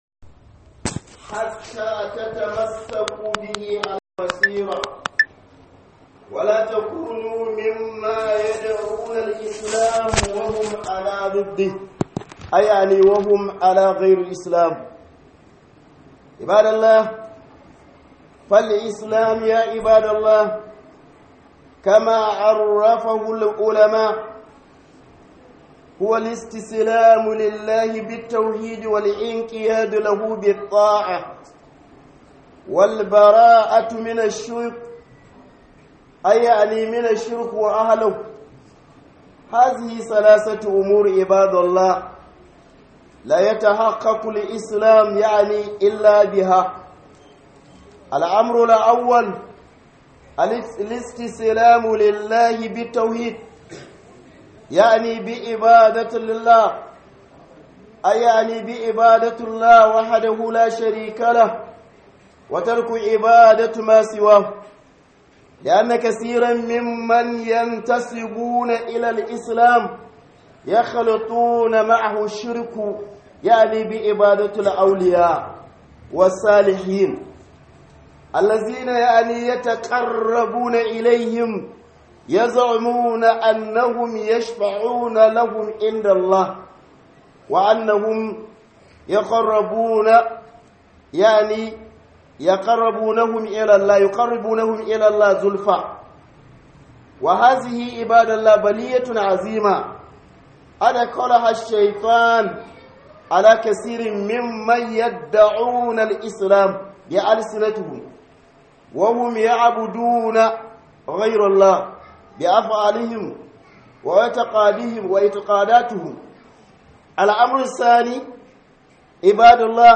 Khuduba - Menene Musulunci
Khudubar Sallar Juma'a